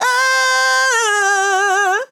TEN VOCAL FILL 2 Sample
Categories: Vocals Tags: dry, english, female, fill, sample, TEN VOCAL FILL, Tension
POLI-VOCAL-Fills-100bpm-A-2.wav